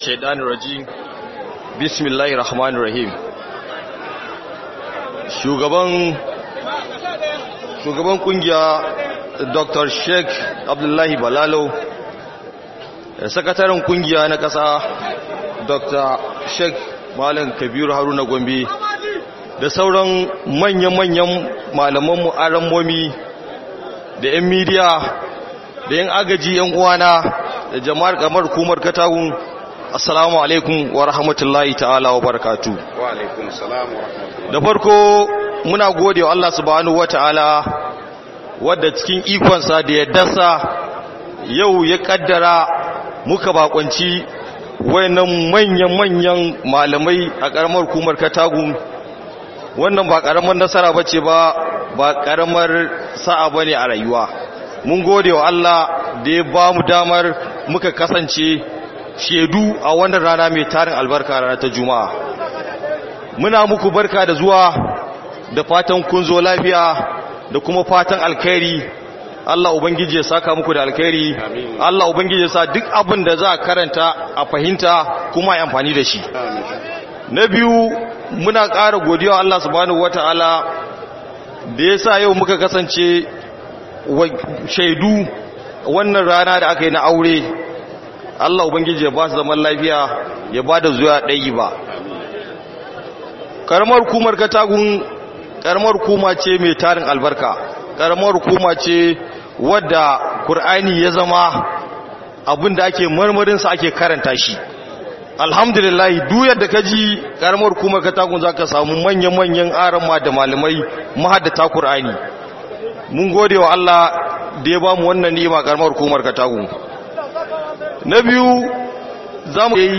Jawabin Shugaban Karamar Hukumar Katagum